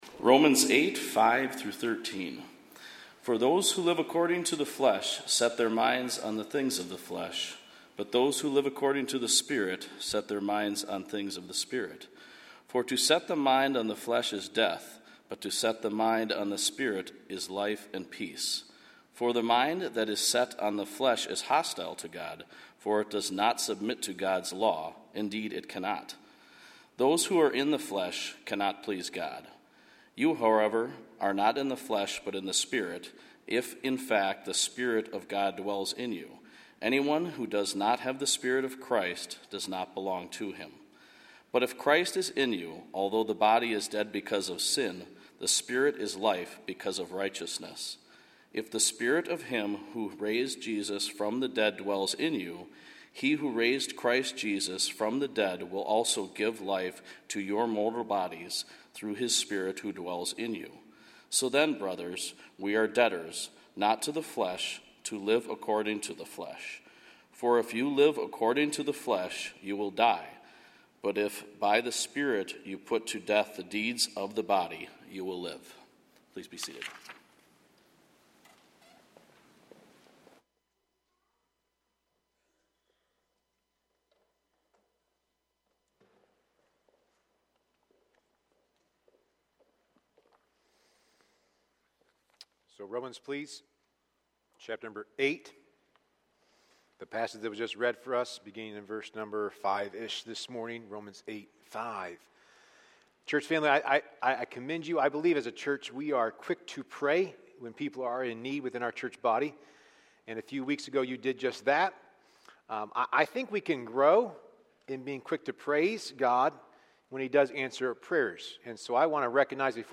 A sermon from the series "Romans."